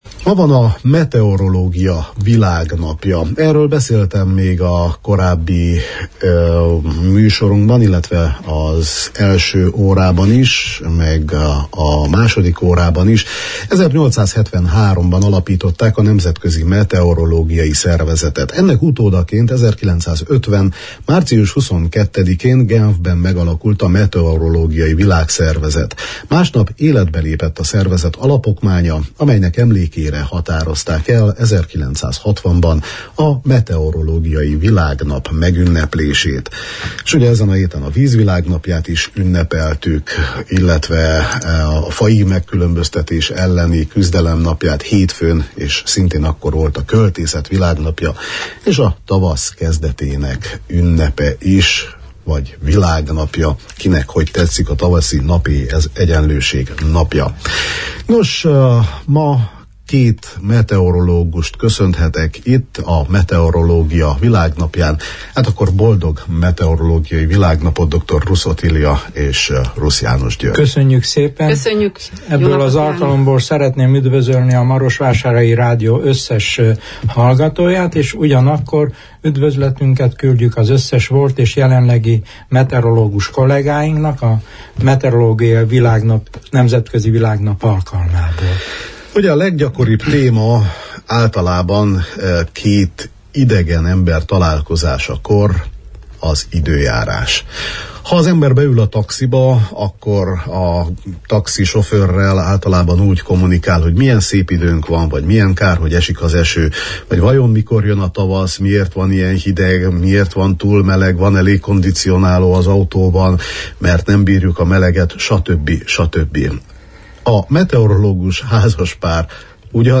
A Jó reggelt, Erdély! műsor vendége egy meteorológus házaspár volt.